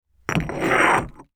Sand_Pebbles_01.wav